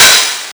Index of /90_sSampleCDs/Club_Techno/Percussion/Hi Hat
Hat_O_04.wav